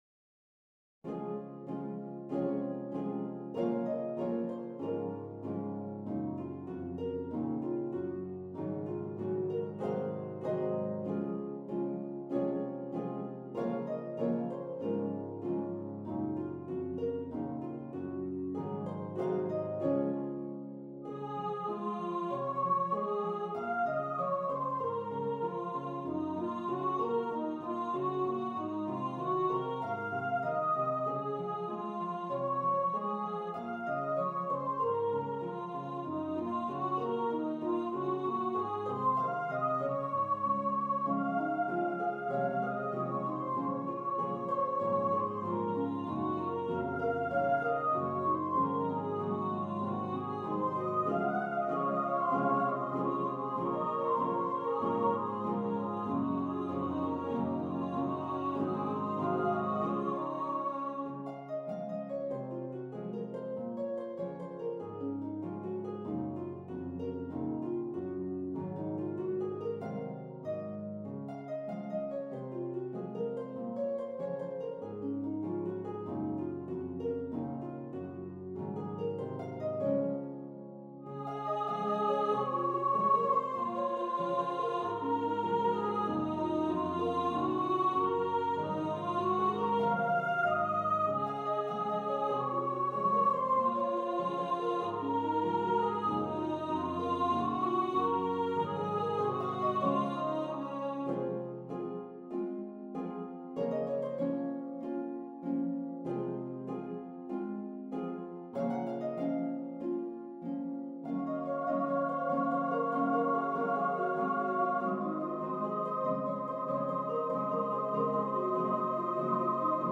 for voices and harp